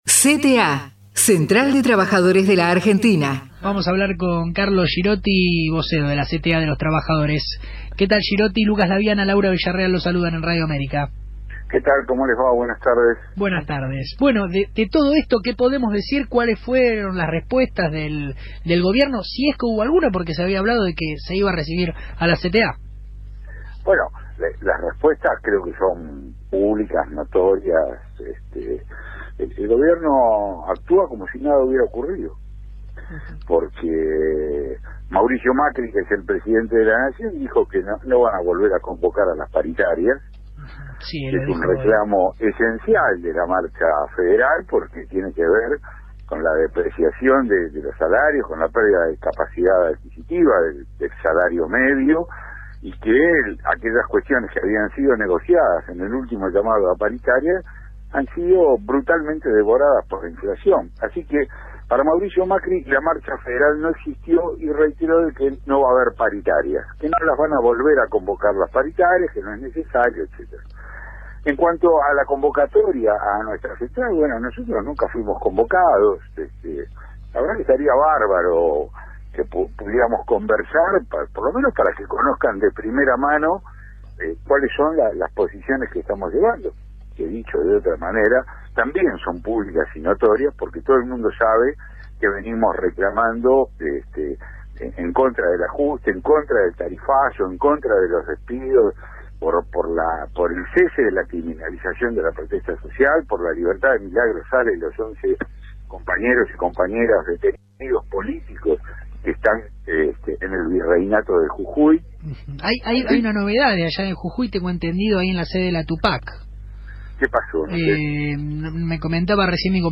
entrevista RADIO AMÉRICA (AM 1190)